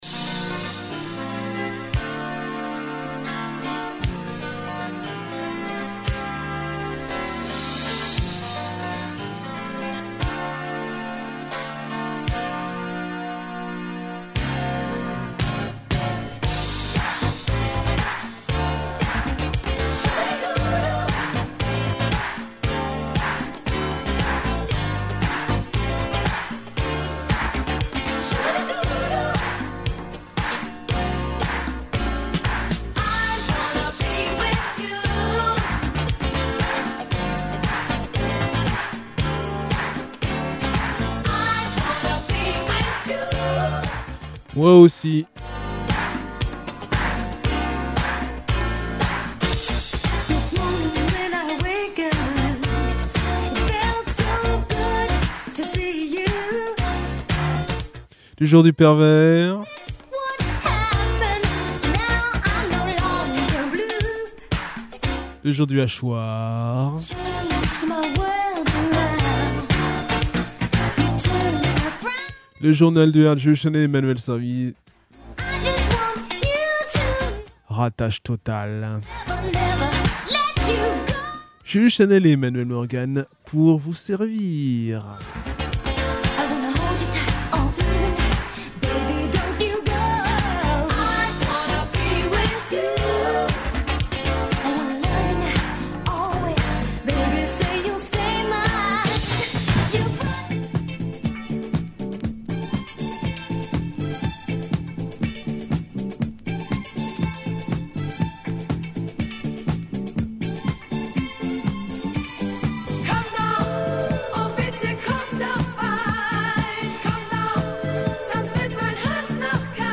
Bienvenue sur le podcast du Dub Corner de Radio Campus Angers